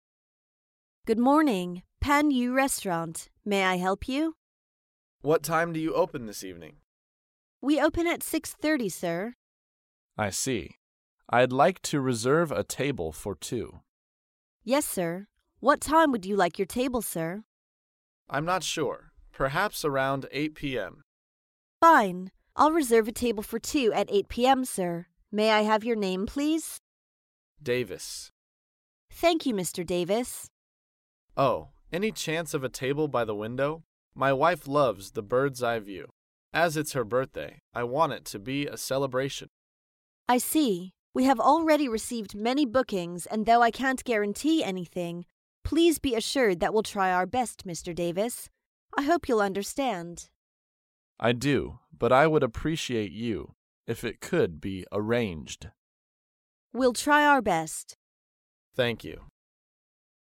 高频英语口语对话 第205期:预定二人餐位 听力文件下载—在线英语听力室
在线英语听力室高频英语口语对话 第205期:预定二人餐位的听力文件下载,《高频英语口语对话》栏目包含了日常生活中经常使用的英语情景对话，是学习英语口语，能够帮助英语爱好者在听英语对话的过程中，积累英语口语习语知识，提高英语听说水平，并通过栏目中的中英文字幕和音频MP3文件，提高英语语感。